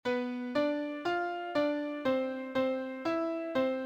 • Translation shifts all the notes in a melody backwards or forwards by a specified number of steps.
Pitch class sequence B D F D C C E C.